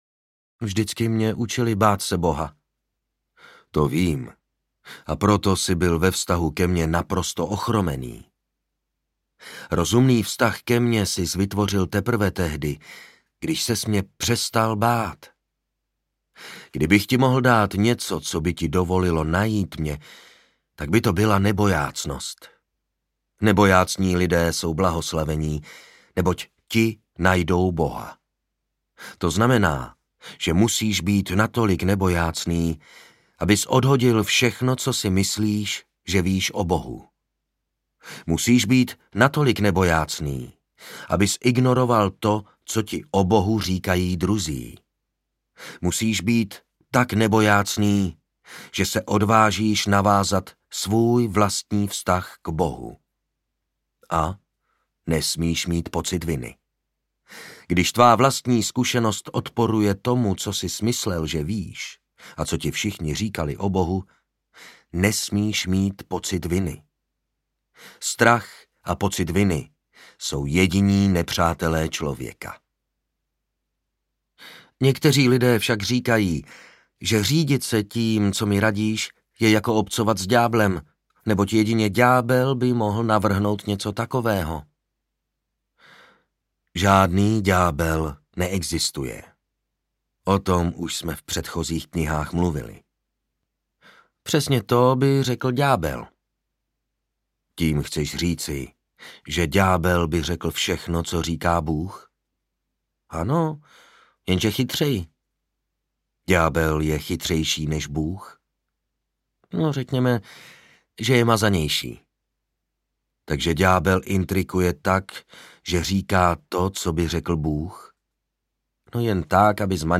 Hovory s Bohem III audiokniha
Ukázka z knihy
Mastering Soundguru.
Vyrobilo studio Soundguru.